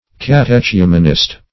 Meaning of catechumenist. catechumenist synonyms, pronunciation, spelling and more from Free Dictionary.
Catechumenist \Cat`e*chu"men*ist\, n.